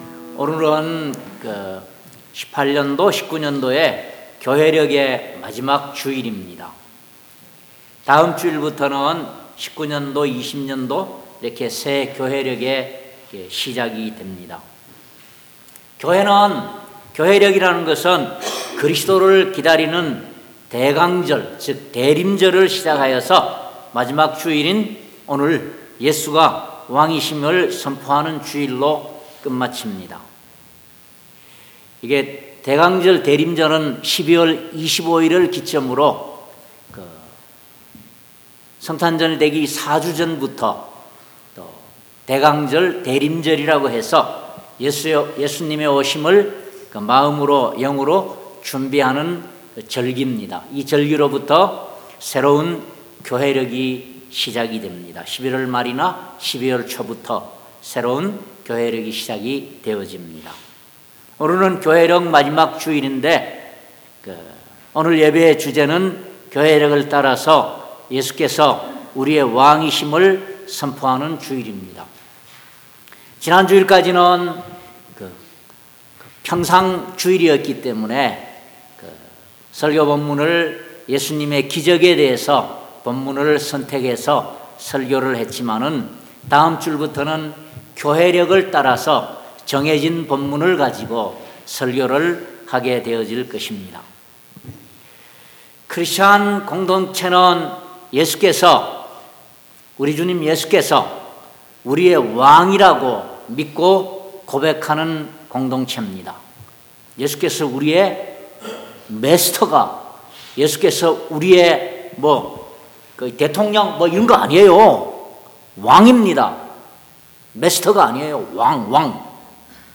골로새서 1:11-20 Service Type: 주일예배 첫째로 예수께서는 창조주라고 말씀하고 있습니다. 16절에는 만물이 그에게서 창조 되었다고 기록되어져 있습니다.